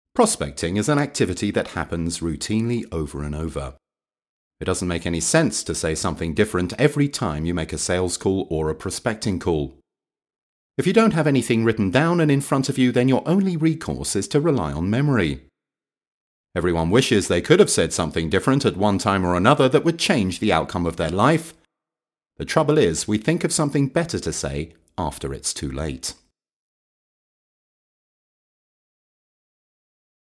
a warm, neutral and versatile British voice
Sprechprobe: eLearning (Muttersprache):
a neutral UK voice